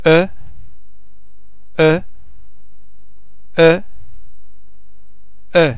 e (shwa) ai on vowel-base similar to ir in (sir)